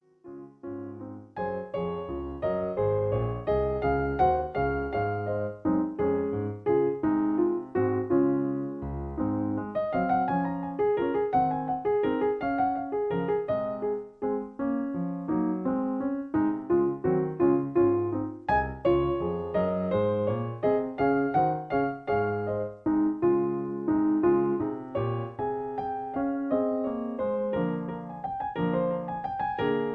In C sharp. Piano Accompaniment. Air for alto.